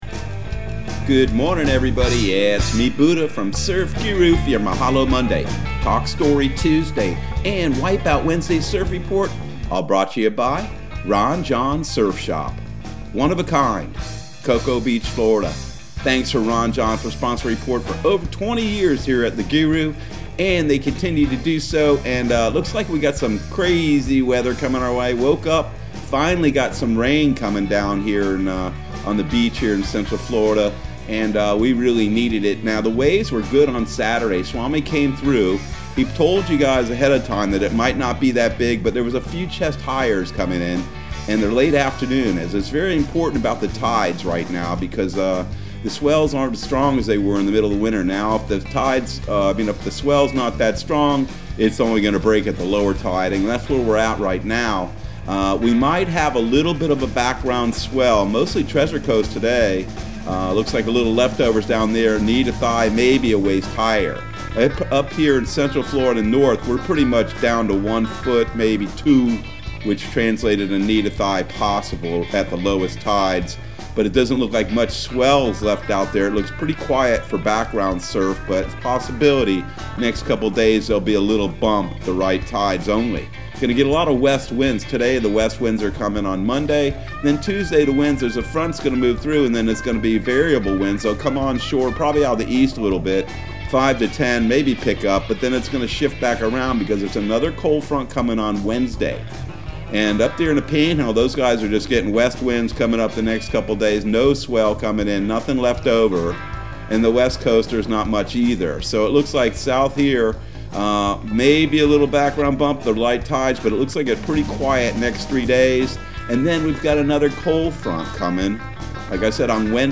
Surf Guru Surf Report and Forecast 04/19/2021 Audio surf report and surf forecast on April 19 for Central Florida and the Southeast.